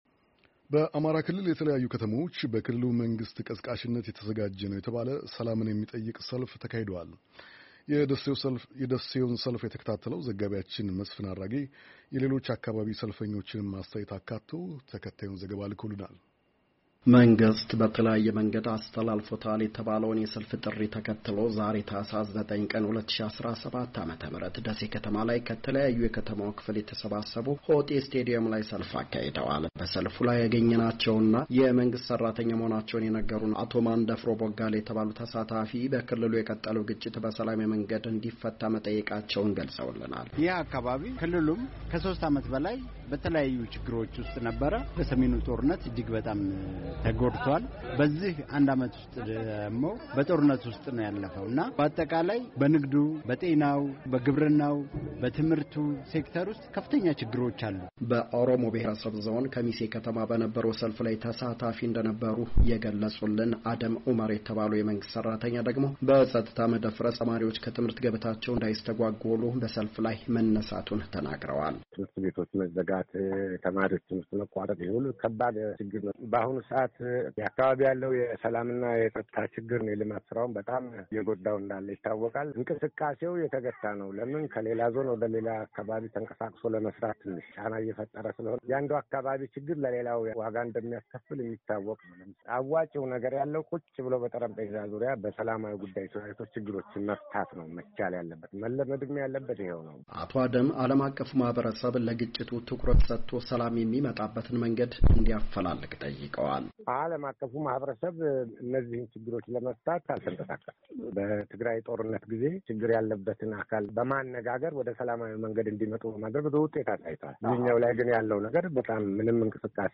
በአማራ ክልል የተለያዩ ከተሞች በክልሉ መንግሥት ቀስቃሽነት የተዘጋጀ ነው የተባለ፣ ሰላምን የሚጠይቅ ሰልፍ ተካሄደ። ደሴ ከተማ በተካሄደው ሰልፍ ላይ ያገኘናቸው ተሳታፊዎች፣ ክልሉ ከግጭት እንዲወጣና ሰላም እንዲመጣ የሚጠይቅ ሐሳብ በሰልፉ ላይ ማንፀባረቃቸውን ተናግረዋል።
በሌላ በኩል ሰልፍ የወጡት በአስገዳጅነት መኾኑን የተናገሩ አንድ የወልድያ ከተማ ነዋሪ፣ “መንግሥት ነዋሪዎች በፈቃዳቸው ሰልፍ እንዲወጡ ቢፈቅድና ትክክለኛውን የሕዝብ ስሜት ቢያዳምጥ ተገቢ ይኾን ነበር” ብለዋል።